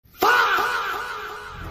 lengendary fahhhh Meme Sound Effect
lengendary fahhhh.mp3